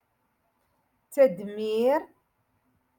Moroccan Dialect- Rotation Six - Lesson Four